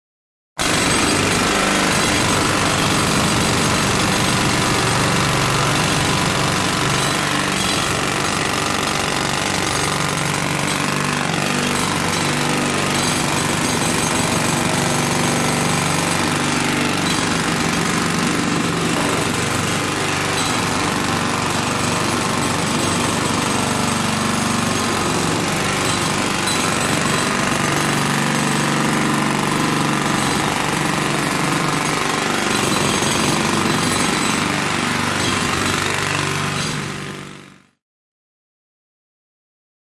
Jackhammer 2; Jackhammer; Hammering Concrete, Metallic Clanking, Steady, Close Perspective.